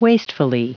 Prononciation du mot wastefully en anglais (fichier audio)
Prononciation du mot : wastefully